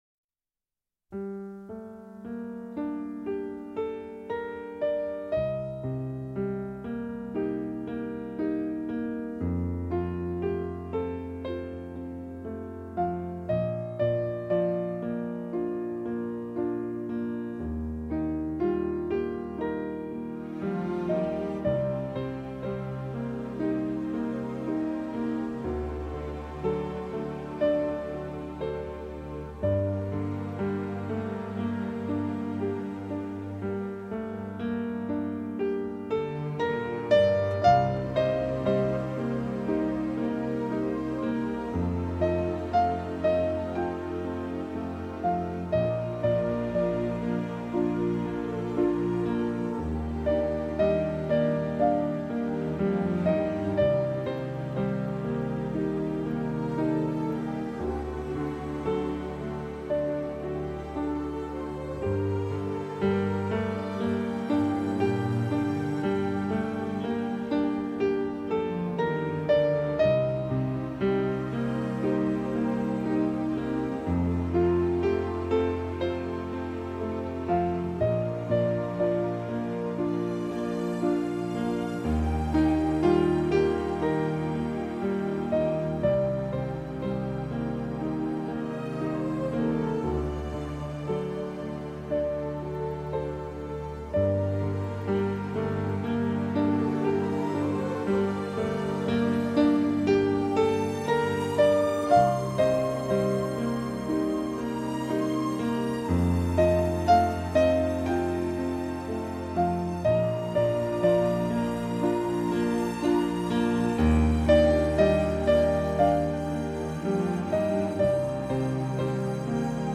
اهنگ ملایم برای محتوای درس